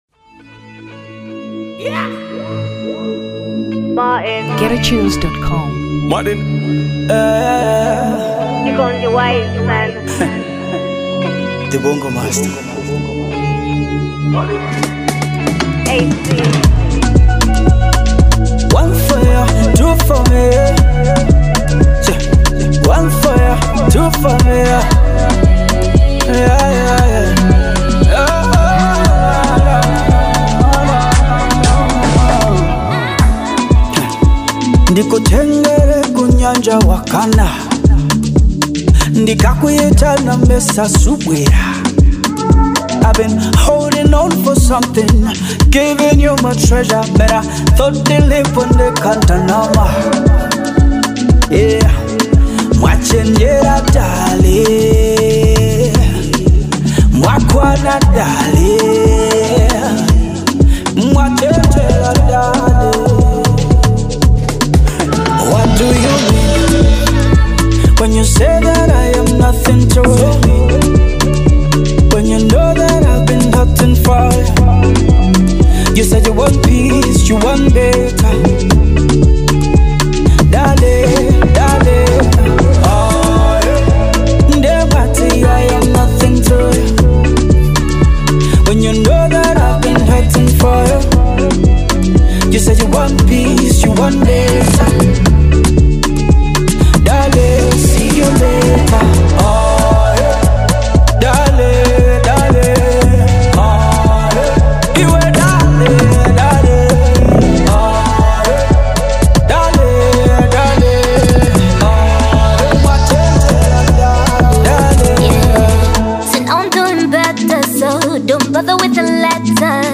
Afro Pop 2023 Malawi